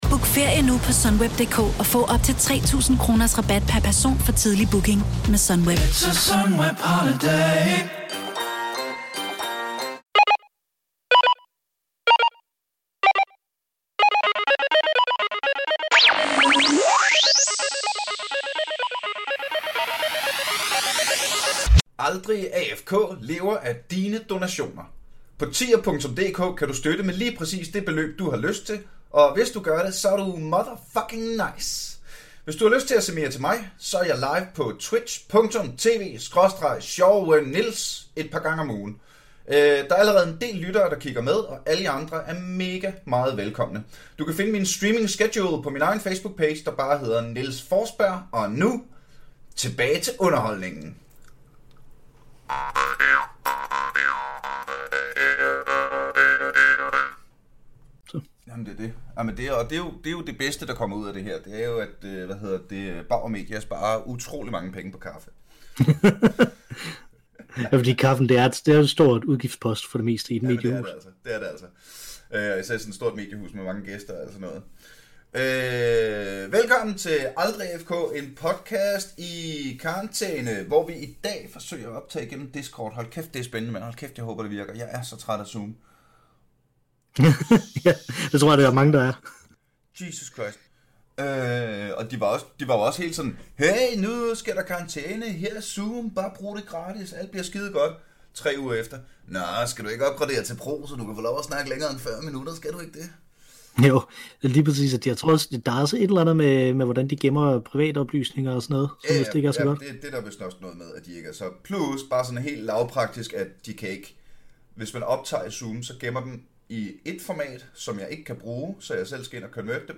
første forsøg på at optage på discord byder på svingende lydnivauer og stabil hyggekvalitet...